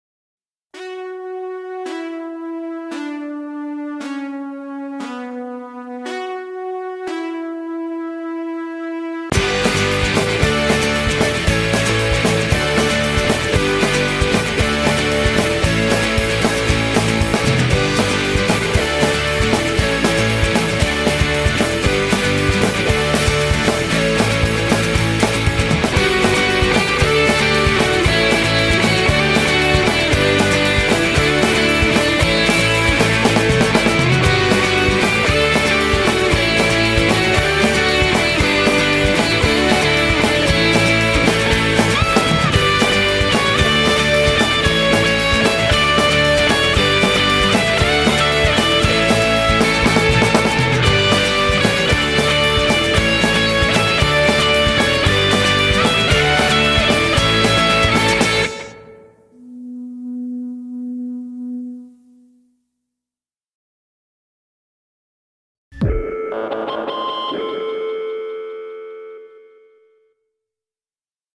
power-pop band